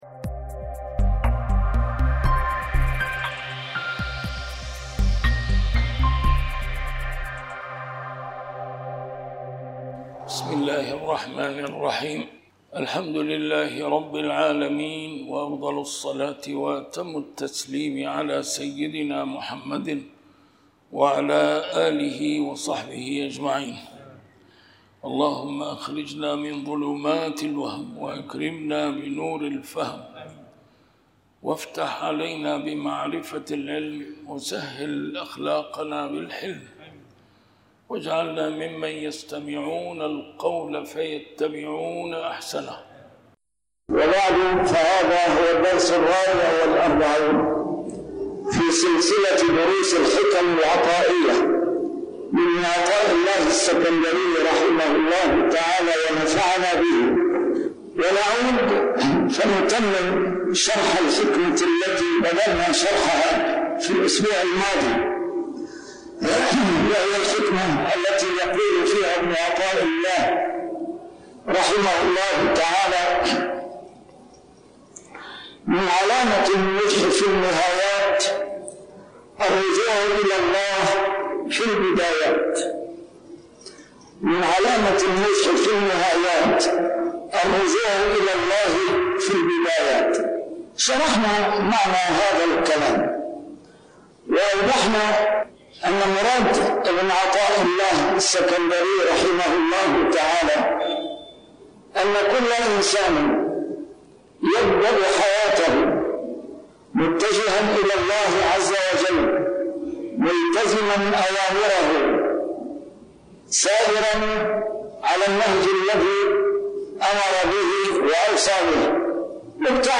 A MARTYR SCHOLAR: IMAM MUHAMMAD SAEED RAMADAN AL-BOUTI - الدروس العلمية - شرح الحكم العطائية - الدرس رقم 44 شرح الحكمة 26+27